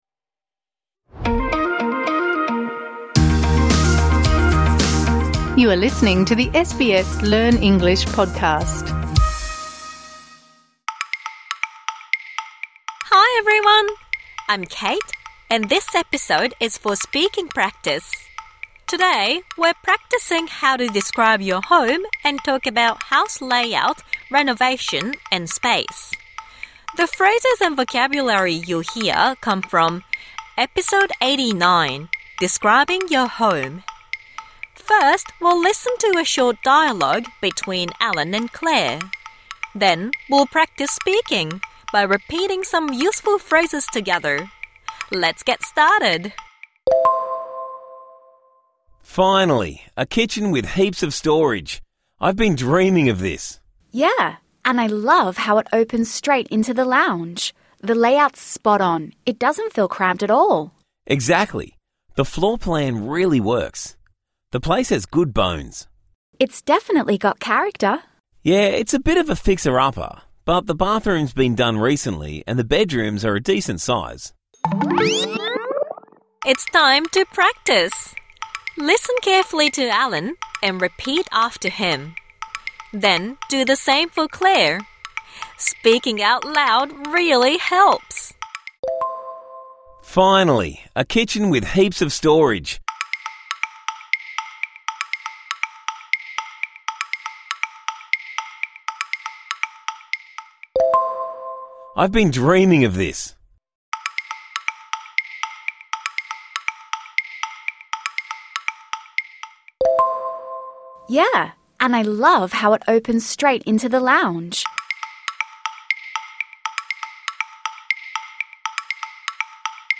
Practise speaking the dialogue from episode #89 Describing your home (Med)